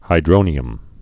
(hī-drōnē-əm)